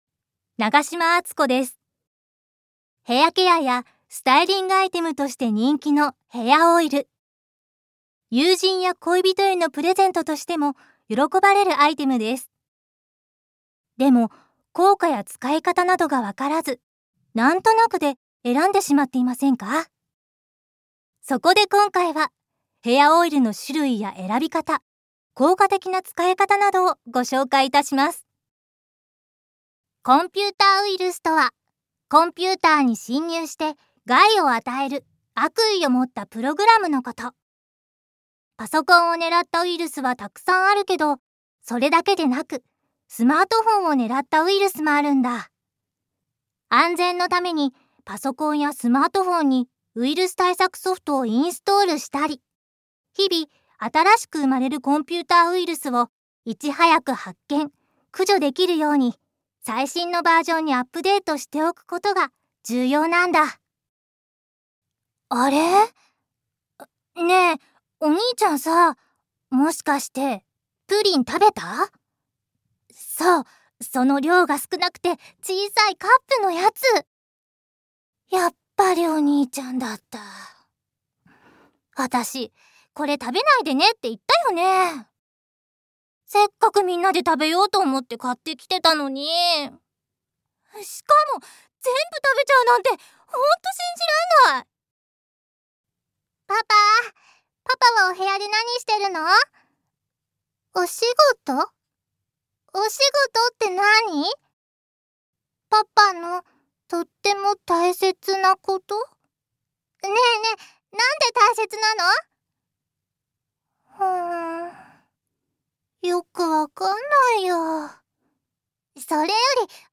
声のタイプ：中～高音程 One shot Voice（サンプルボイスの視聴）
小動物、子供（特に女の子）から落ち着いた女性